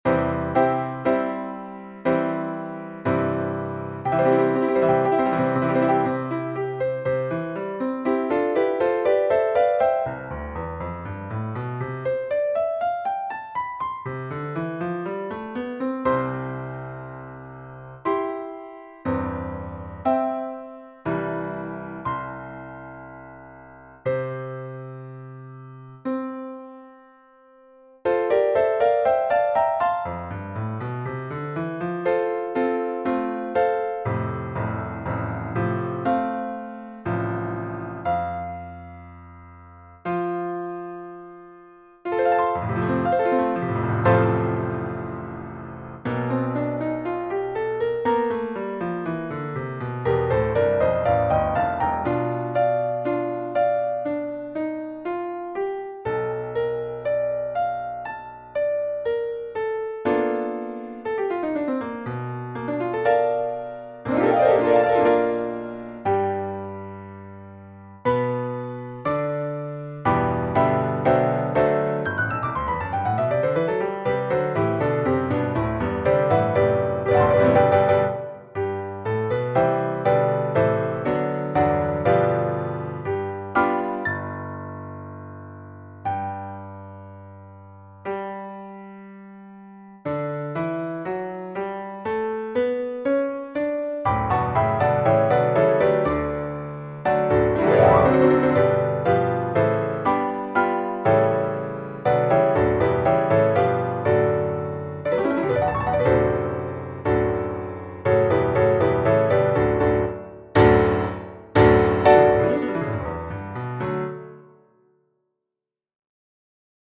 A MuseScore composition played via Grand Piano synth.